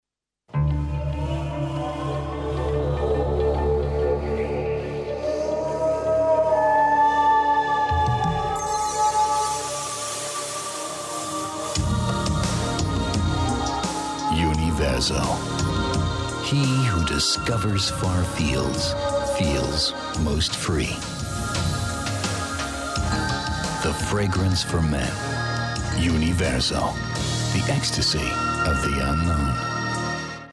Profi-Sprecher englisch (us).
Kein Dialekt
Sprechprobe: eLearning (Muttersprache):
english voice over artist.